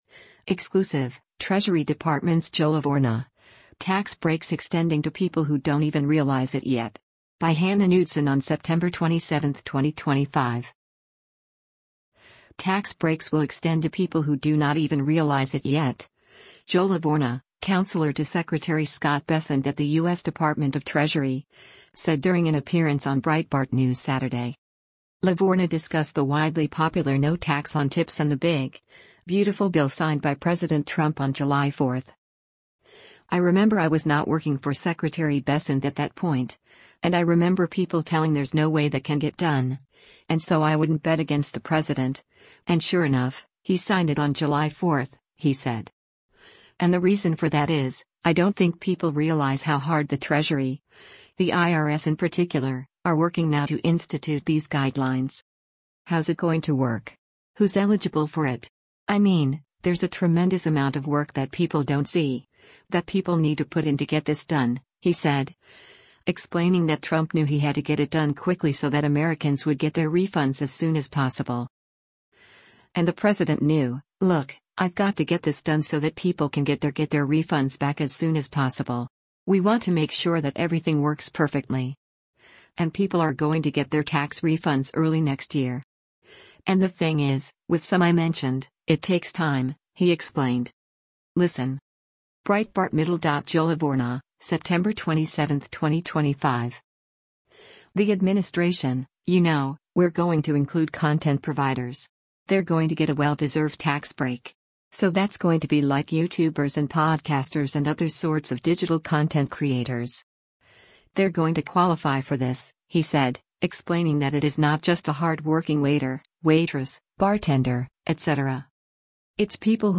Tax breaks will extend to people who do not even realize it yet, Joe Lavorgna, counselor to Secretary Scott Bessent at the U.S. Department of Treasury, said during an appearance on Breitbart News Saturday.